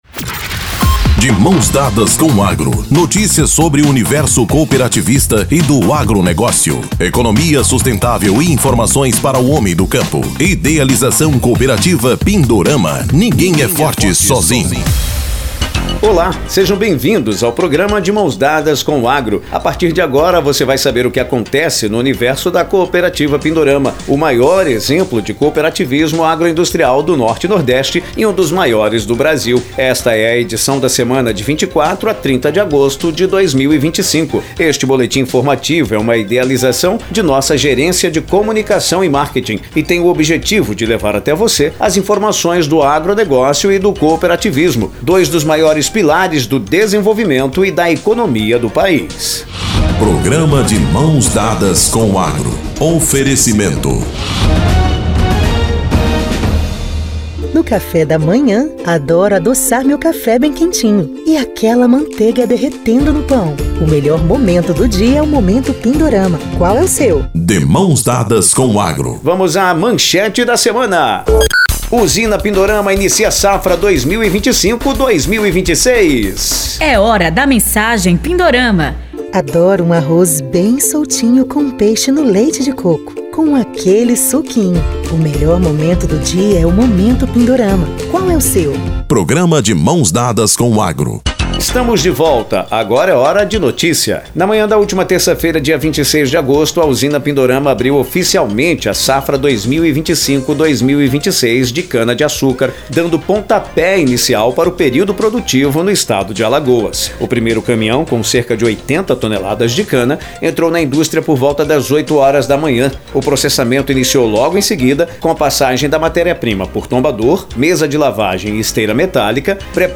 Boletim da cooperativa destaca início da moagem da cana em Alagoas, geração de empregos e expectativas para o novo ciclo produtivo